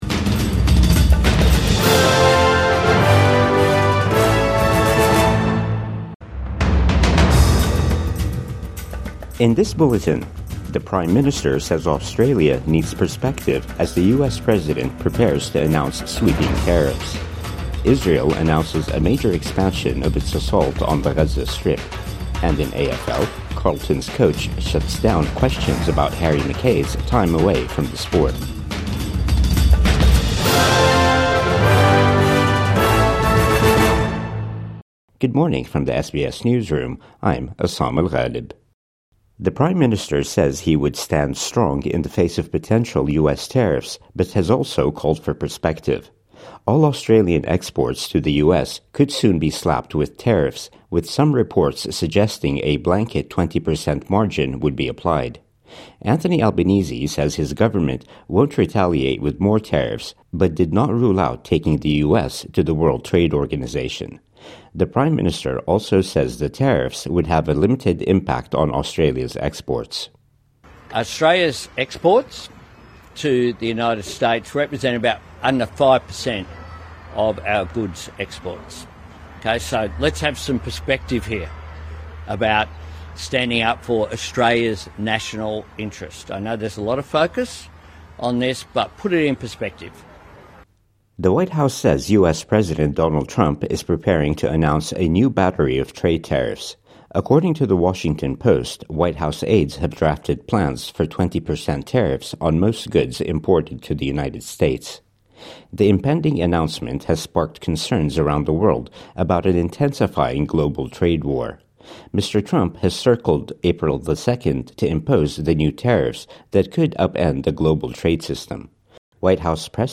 Tariffs: PM says Australia will stand firm | Morning News Bulletin 3 April 2025